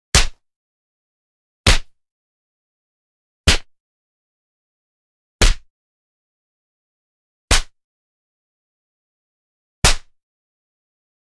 На этой странице собраны разнообразные звуки шлепков — от легких хлопков до сочных ударов.
Звуки пощечин и шлепков по лицу: Звуки ударов ладонью